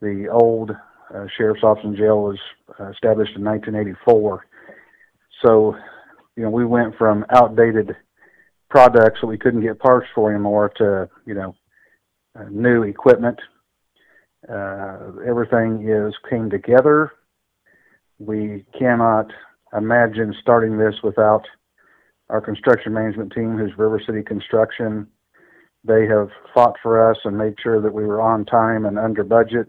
Johnson says this is a significant step forward for his department, as well as the County Attorney’s Office and jail.